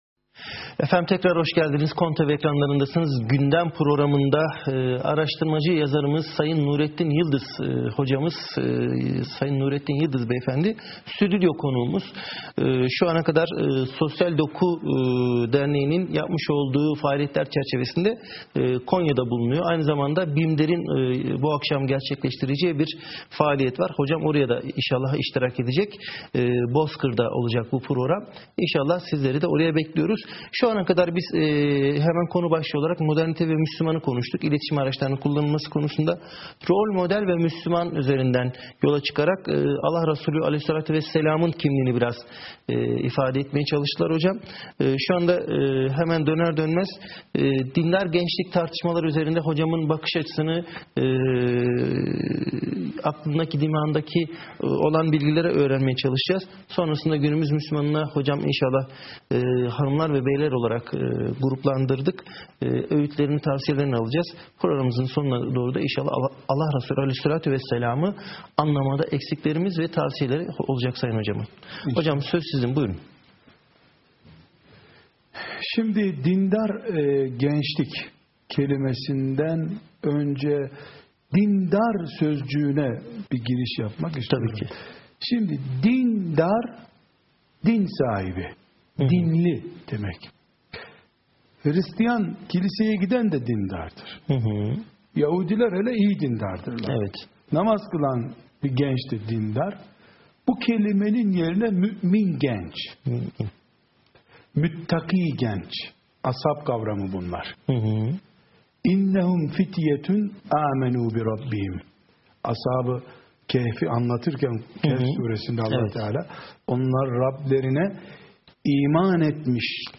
12) KONTV Canlı Yayın 2.Bölüm | Sosyal Doku TV